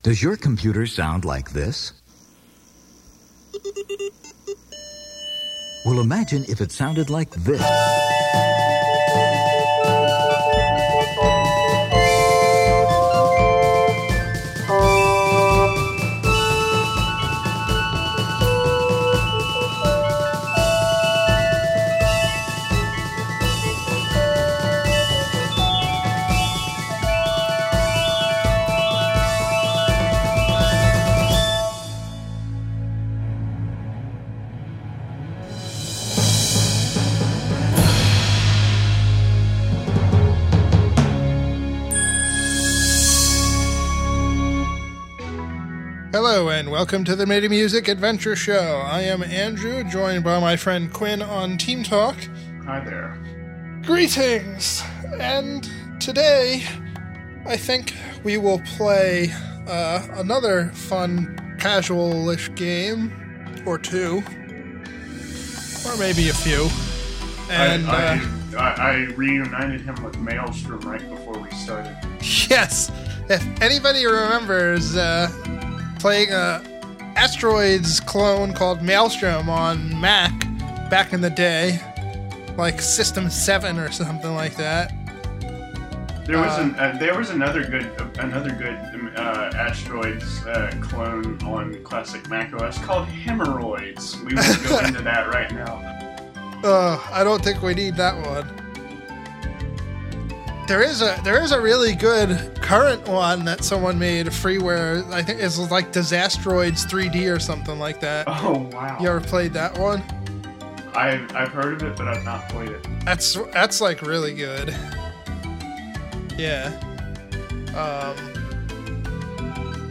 In this show we play Telltale’s Poker Night 2, pretty funny game. Then I try a new game called God Mode. Bit of a relaxed show, as I believe I was still a bit under the weather at the time.